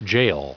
Prononciation du mot jail en anglais (fichier audio)
jail.wav